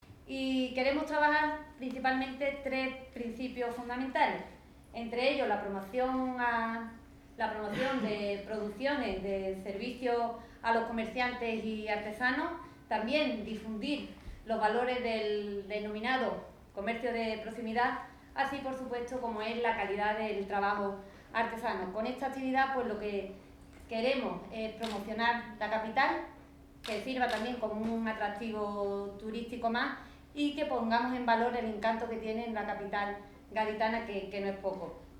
La vicepresidenta de la Fundación Provincial de Cultura de la Diputación, Vanesa Beltrán; y la teniente de alcalde delegada de Desarrollo Económico y Fomento de Empleo, Turismo y Comercio, Beatriz Gandullo; han presentado en el Ayuntamiento de Cádiz la XXIV edición del Mercado Andalusí de Cádiz, que se celebrará en la ciudad durante los días 4, 5 y 6 de agosto.